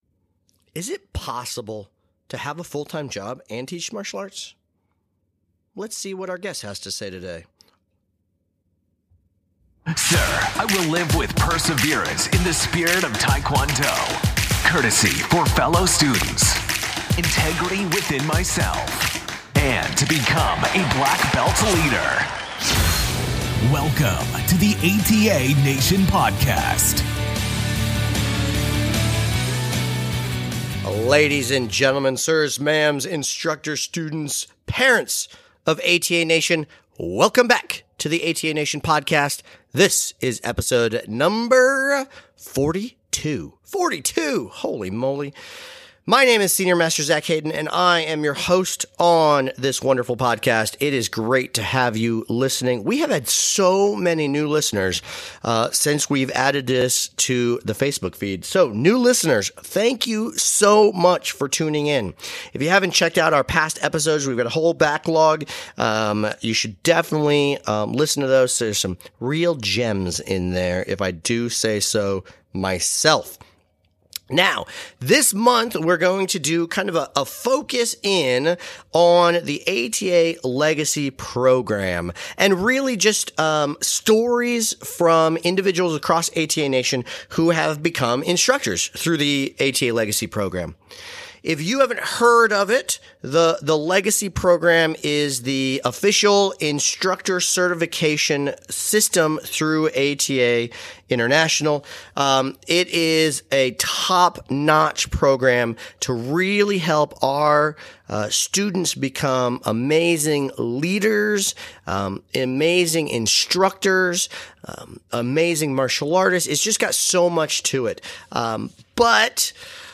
Ep. 42 - Legacy Instructor interview